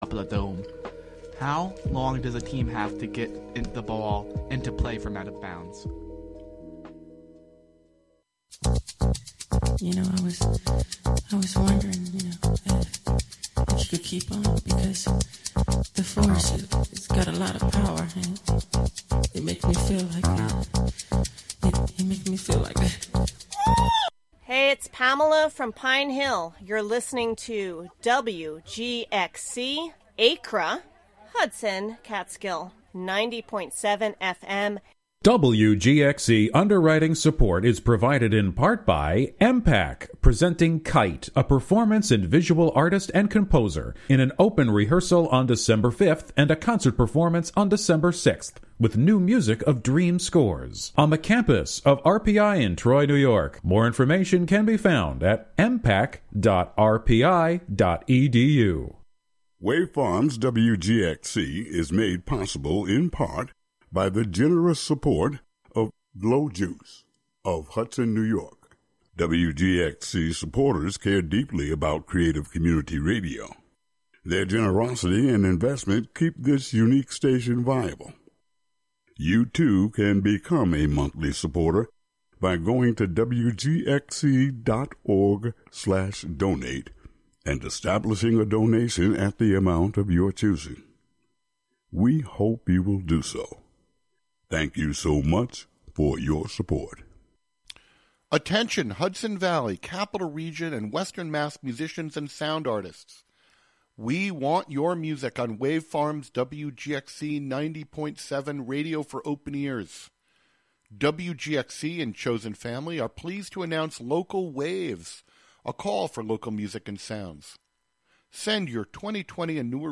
Featuring gospel, inspirational, soul, R&B, country, christian jazz, hip hop, rap, and praise and worship music of our time and yesteryear, interwoven with talk, interviews, and spiritual social commentary